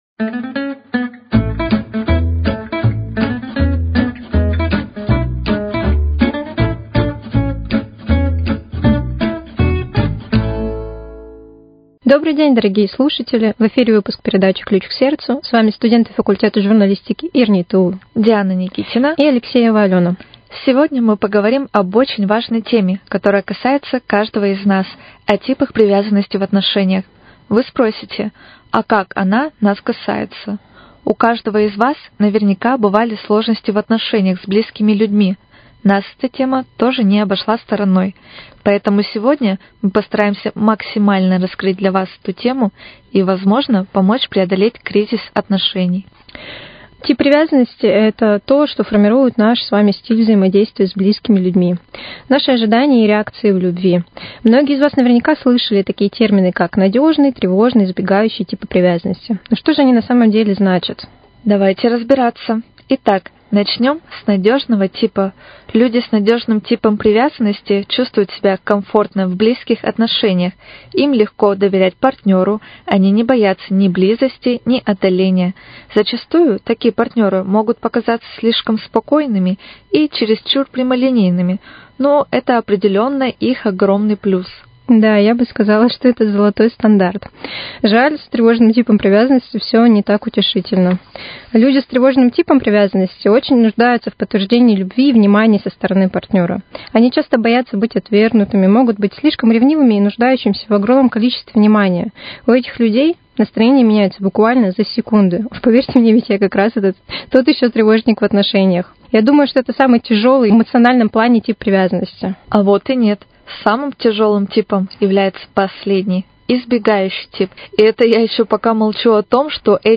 Ключ к сердцу: Беседа